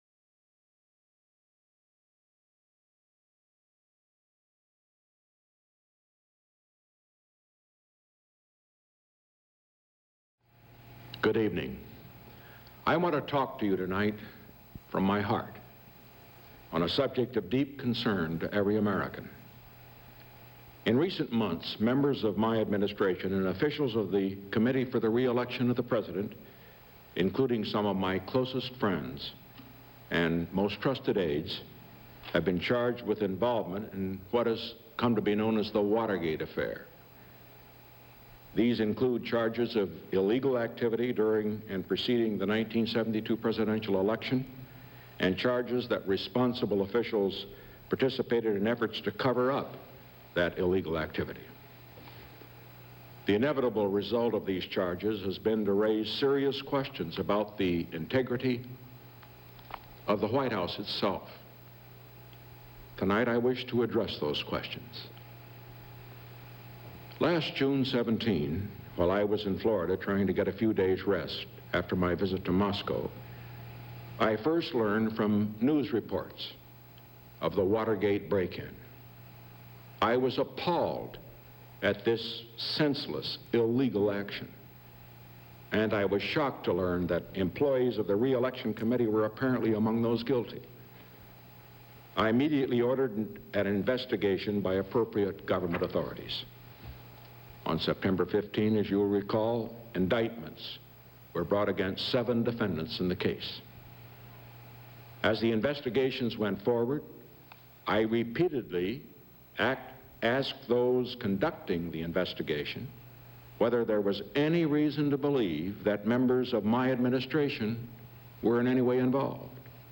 April 30, 1973: Address to the Nation About the Watergate Investigations